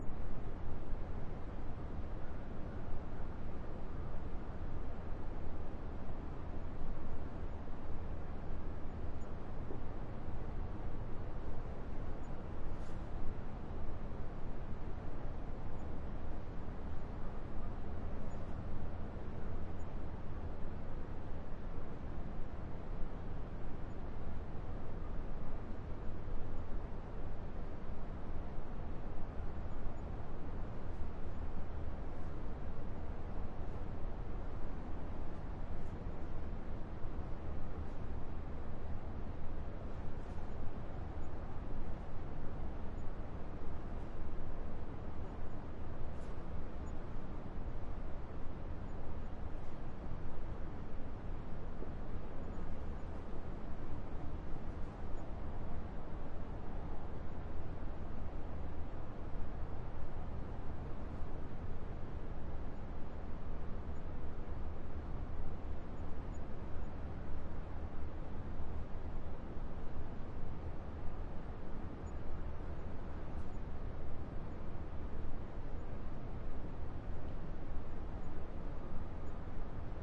campground » room tone large shed heavy ambience with distant trains and bassy +occasional drips and creaks
标签： trains distant large room tone shed
声道立体声